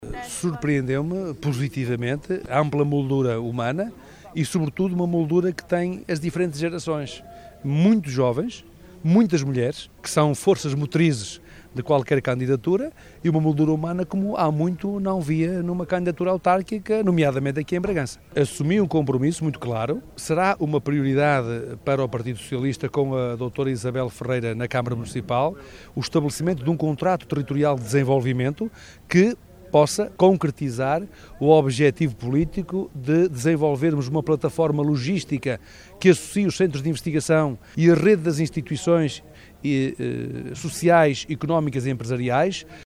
Na apresentação marcou presença o Secretário Geral do PS, José Luís Carneiro, que se mostrou surpreso pela “ampla moldura humana” e deixa um compromisso a Isabel Ferreira.